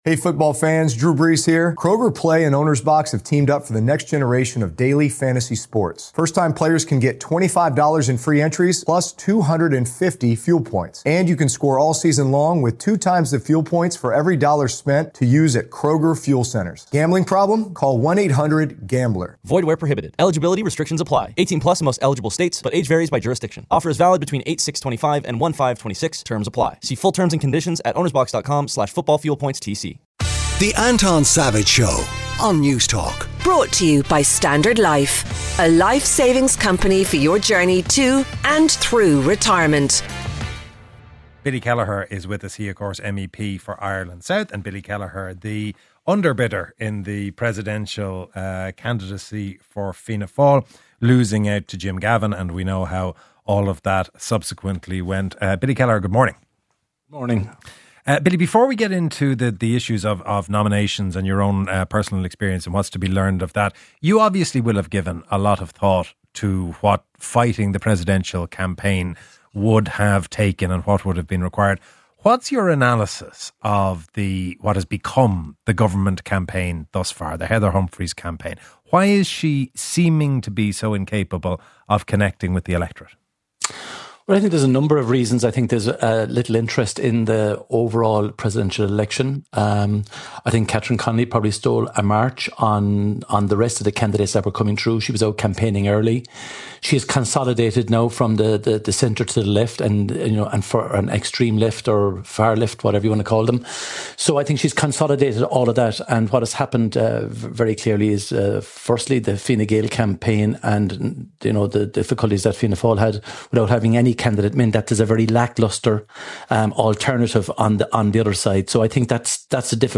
Fianna Fáil’s internal nomination process has left some disappointment, while the presidential campaigns of Catherine Connolly and Heather Humphreys continue to build momentum. Billy Kelleher, MEP for Ireland South, joins us to discuss.